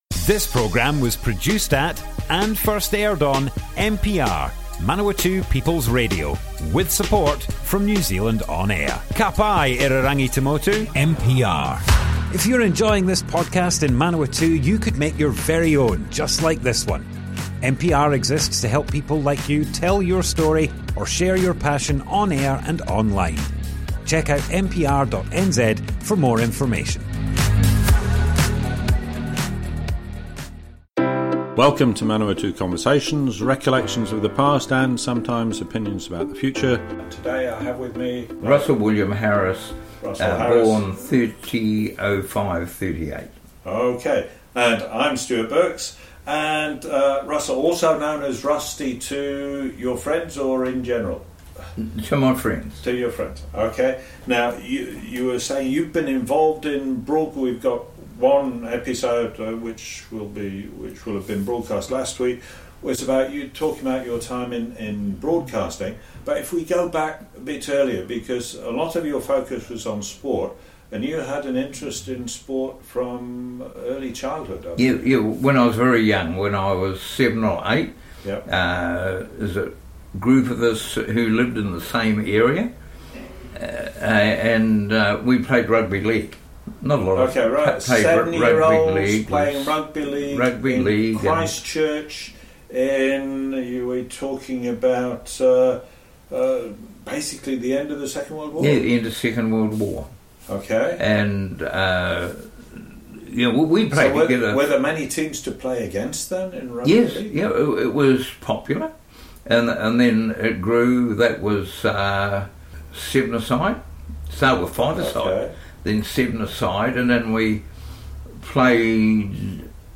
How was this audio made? Manawatu Conversations More Info → Description Broadcast on Manawatu People's Radio, 19th December 2023, Part 2 of 2.